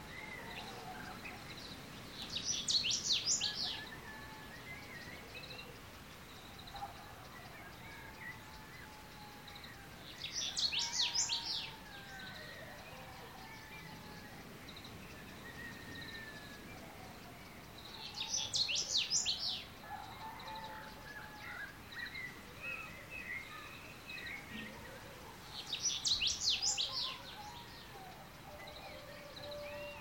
Bruant striolé - Mes zoazos
Son chant mélodieux et discret est souvent entendu à l’aube, marquant sa présence dans les régions désertiques et semi-désertiques d’Afrique du Nord.
bruant-striole.mp3